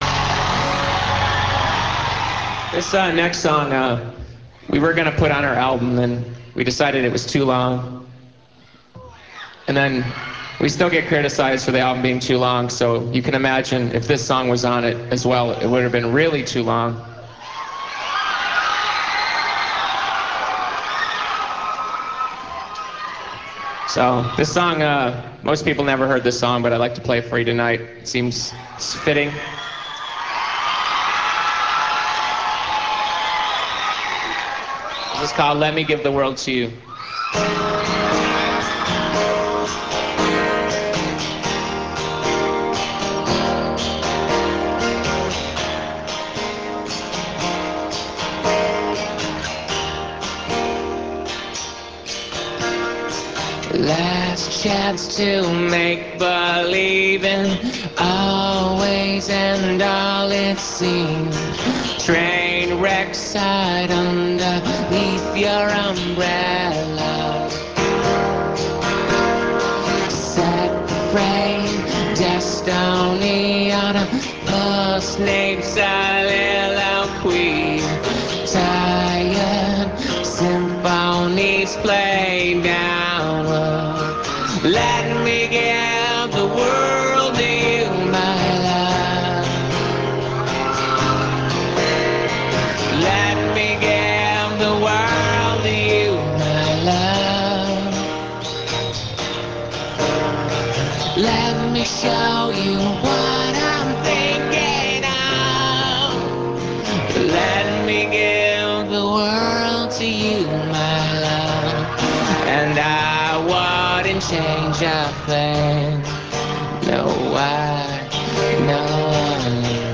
Aerial Theater; Houston, USA
acoustique. on line